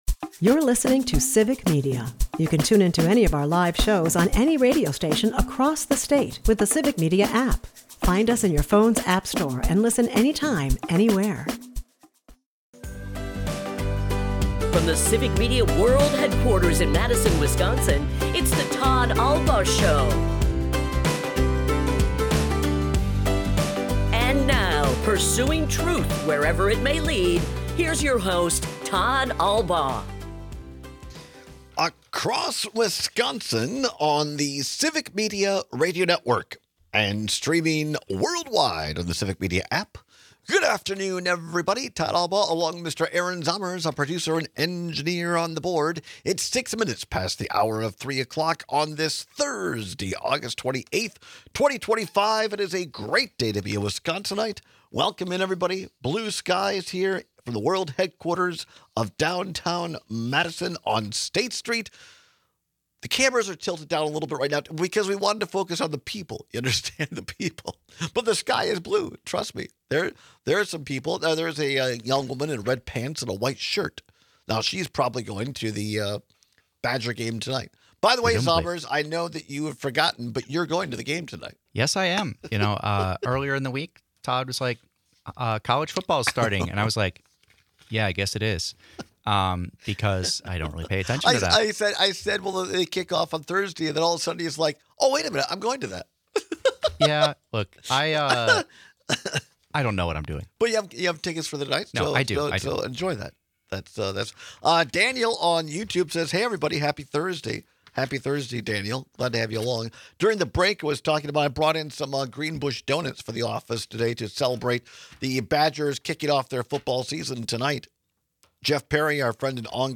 People have some very strong opinions, so we take your calls and texts!